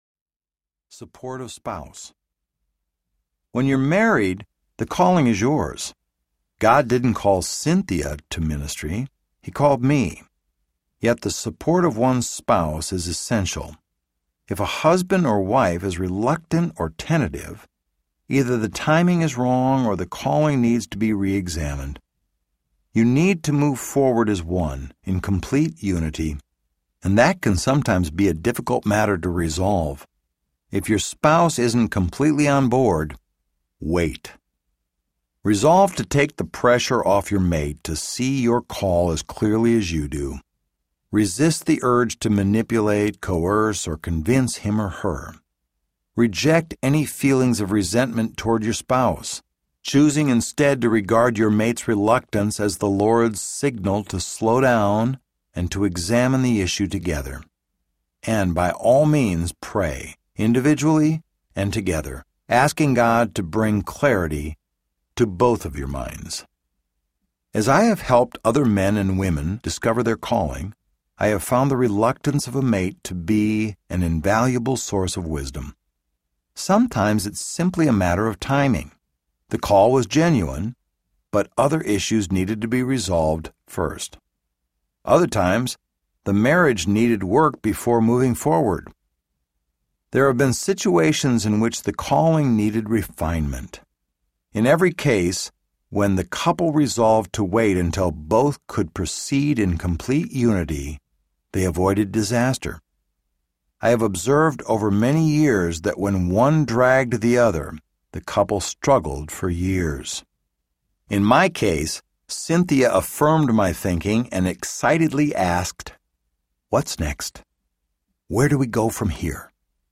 Saying It Well Audiobook
Narrator
9 Hrs. – Unabridged